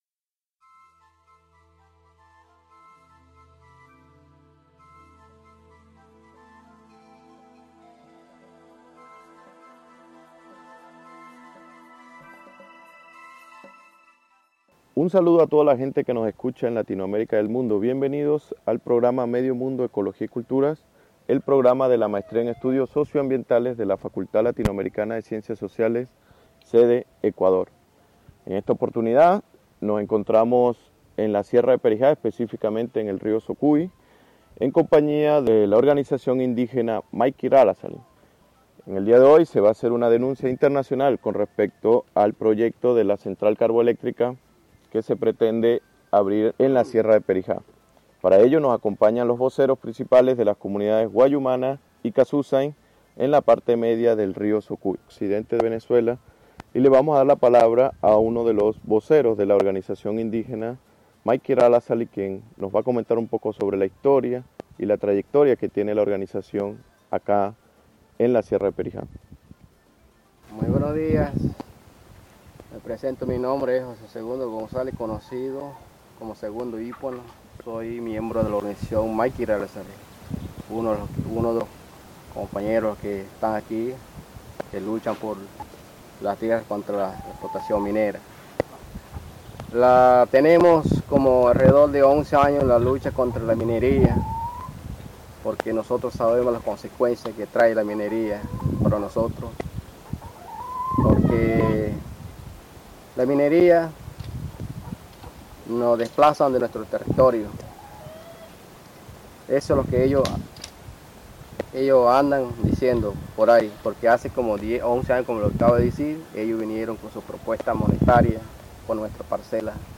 La denuncia internacional de la organización indígena Wayuu Maikiraalasalii frente al proyecto del gobierno venezolano de construir una central carboeléctrica en la Sierra de Períja, expandiendo la minería del carbón a cielo abierto hacia el sur, sacrificando la única fuente de agua del norte del estado Zulia, sus tierras comunitarias y sus formas de vida. El programa fue grabado en octubre del 2013 a orillas del río Socuy. Disfruten además de un canto wayuu "Jayechi", expresión de esta lucha por la vida y la dignidad.